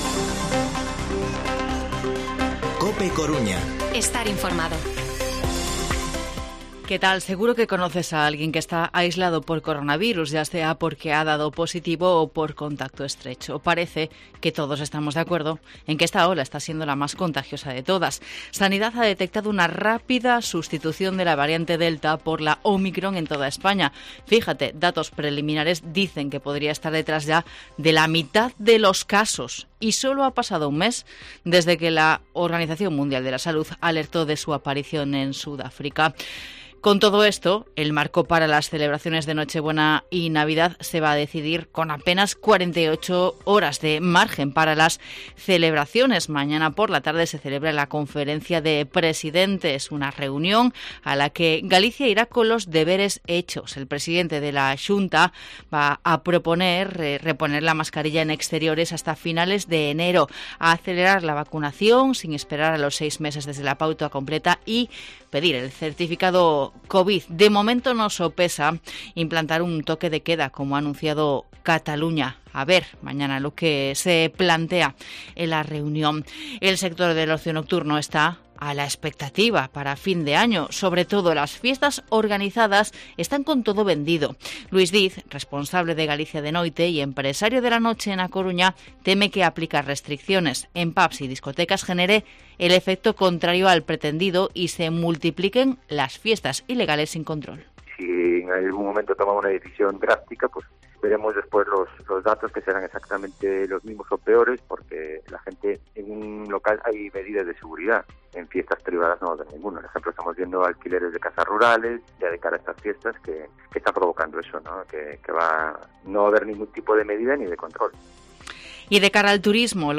Informativo Mediodía COPE Coruña martes, 21 de diciembre de 2021 14:21-1430